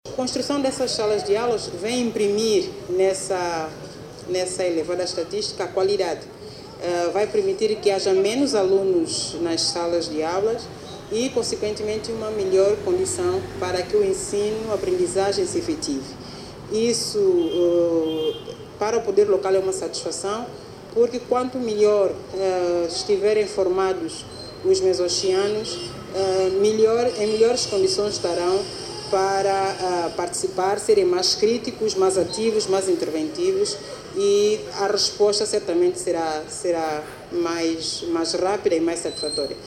(Pode ouvir a Presidente de Mezochi )